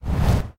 snd_mage_fire.ogg